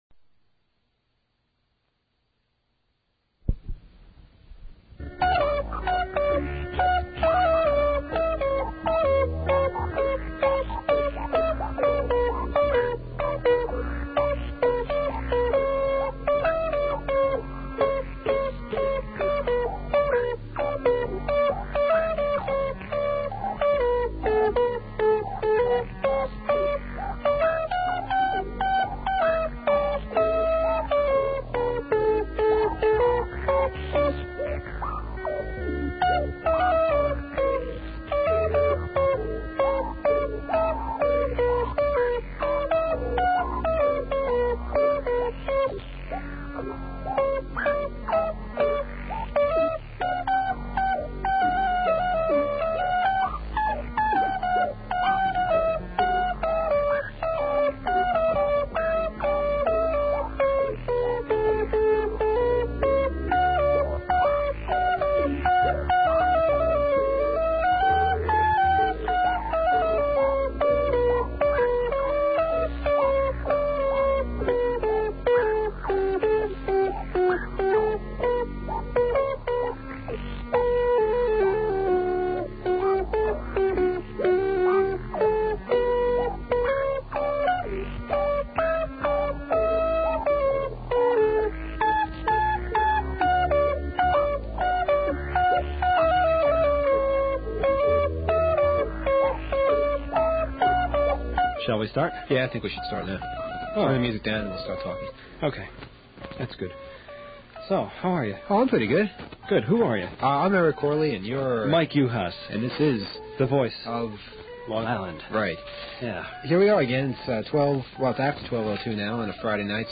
A nasty noise